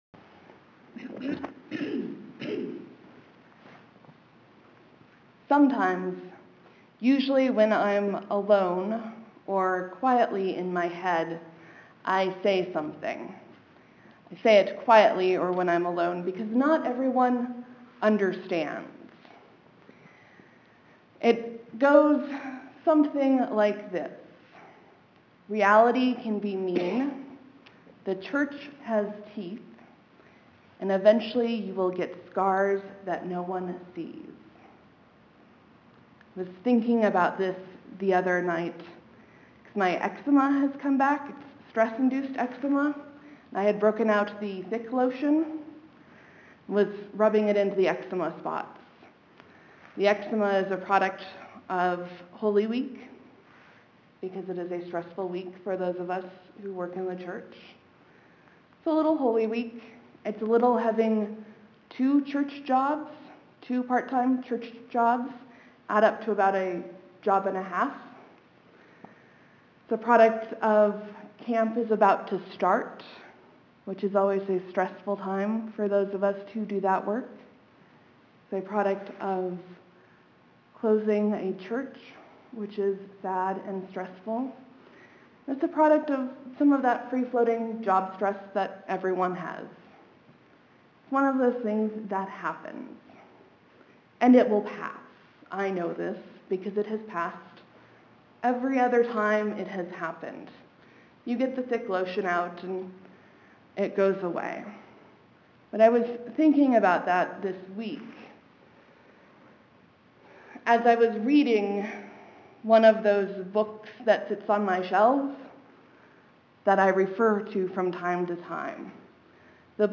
How Incarnation Ends, a sermon for Good Friday 3-29-13